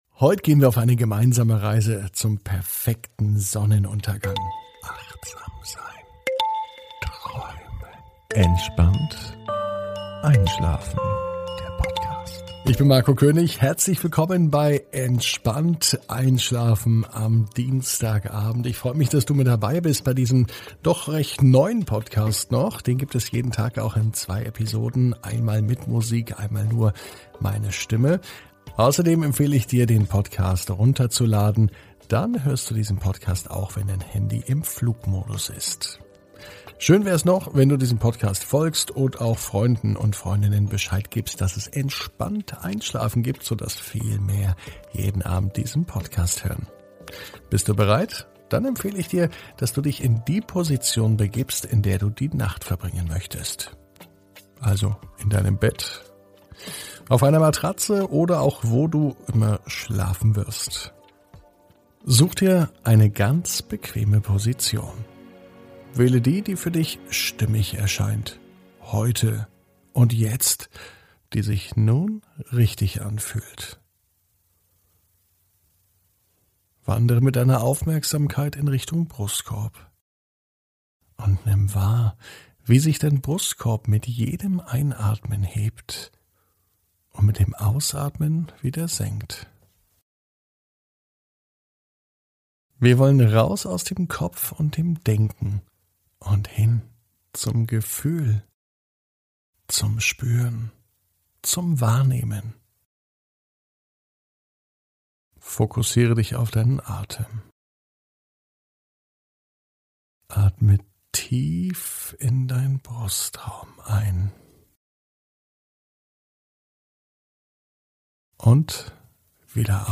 (ohne Musik) Entspannt einschlafen am Dienstag, 11.05.21 ~ Entspannt einschlafen - Meditation & Achtsamkeit für die Nacht Podcast